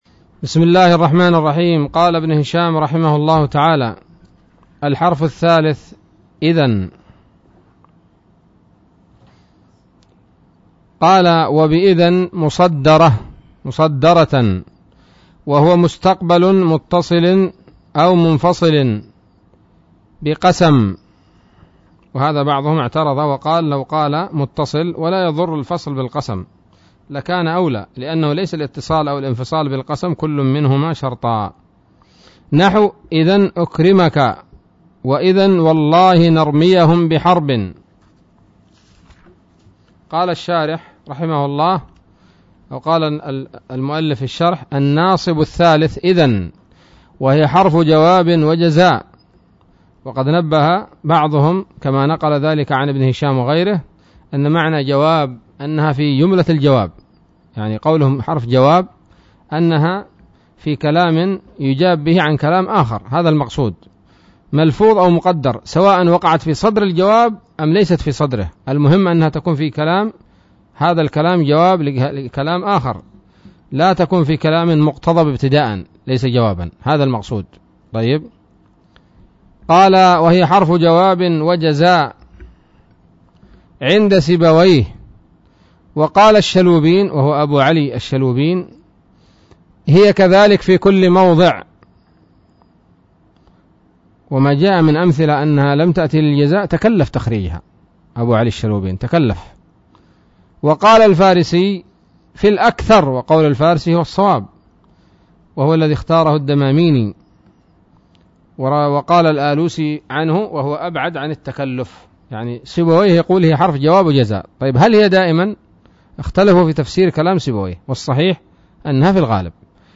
الدرس التاسع والعشرون من شرح قطر الندى وبل الصدى